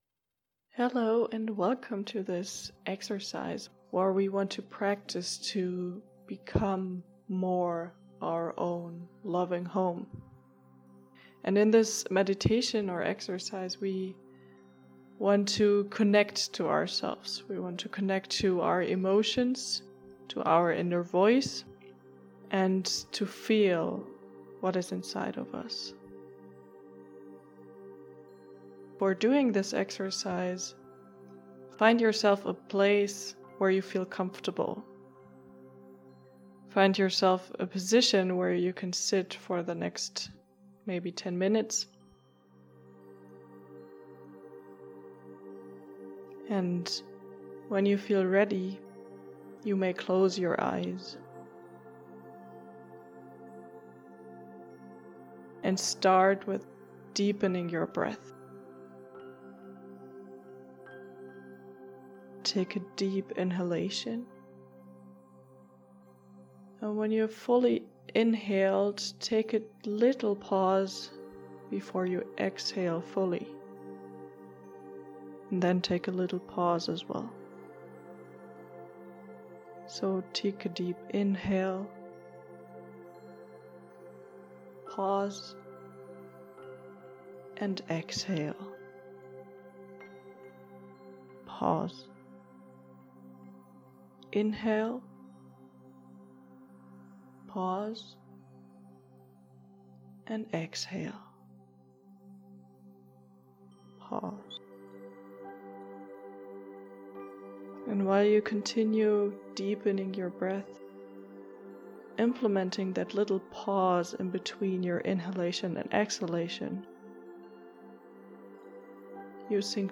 This meditation guides you to connect deeper to yourself and to listen to your emotions, thoughts and inner voice.